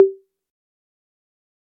Click-Short.ogg